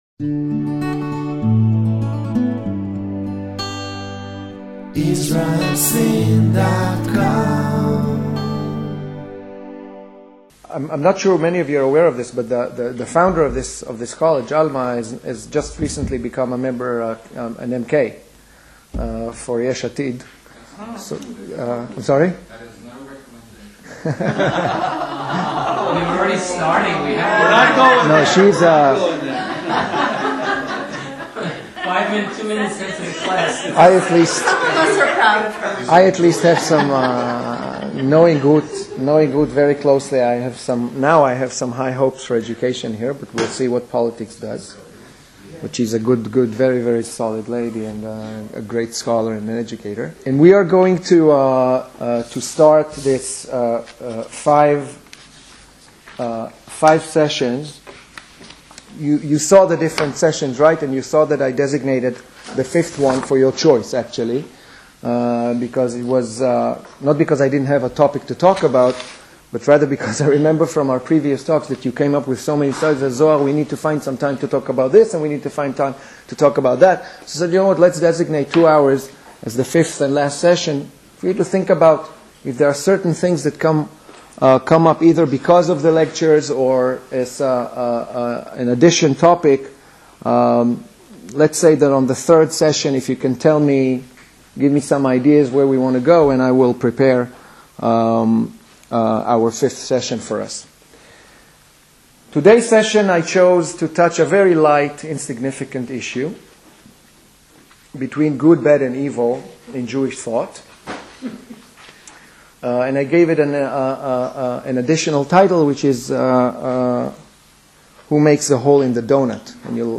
Hebrew Culture in English